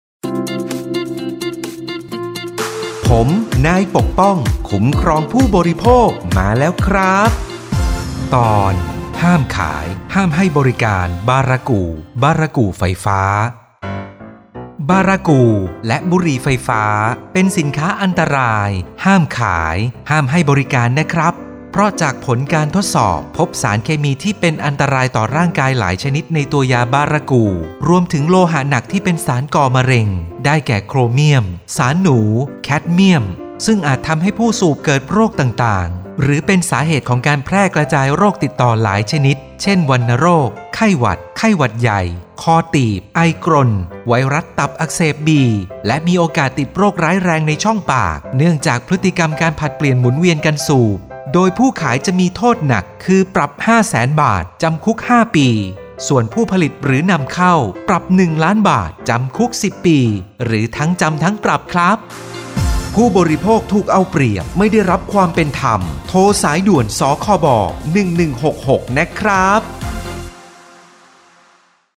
สื่อประชาสัมพันธ์ MP3สปอตวิทยุ ภาคกลาง
019.สปอตวิทยุ สคบ._ภาคกลาง_เรื่องที่ 19_.mp3